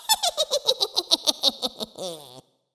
the-sound-of-laughing-witch